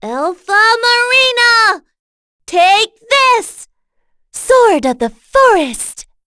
voices / heroes / en
Dosarta-Vox_Skill7.wav